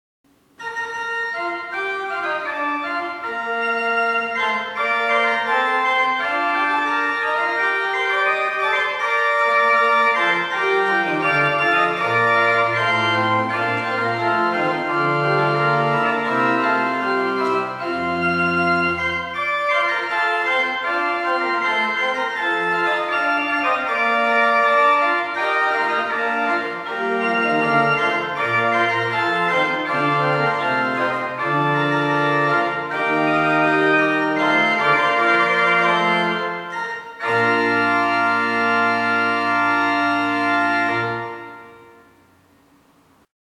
The sound, in fact, is very similar to a Tannenberg organ.
Played on the Principal 8', Floet 8', Principal 4', Quint 3', Octav 2' and the pedal Violon 16' (last chord only).